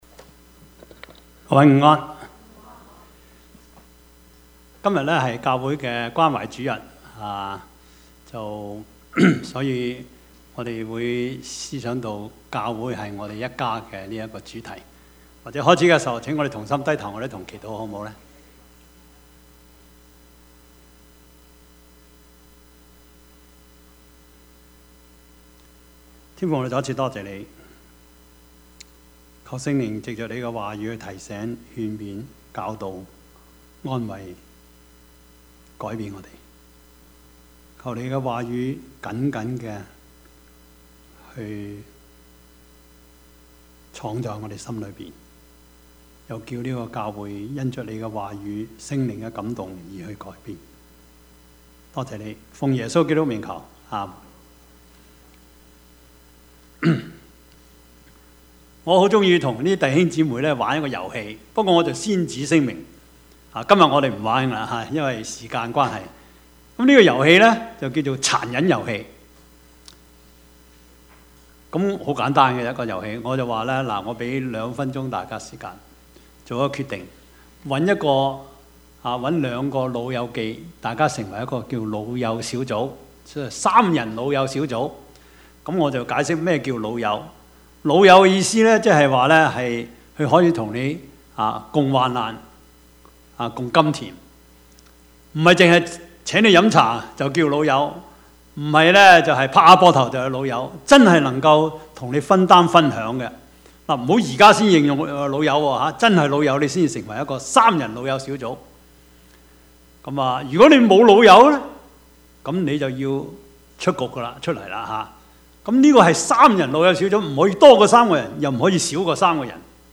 Service Type: 主日崇拜
Topics: 主日證道 « 教學相長 生之盼 »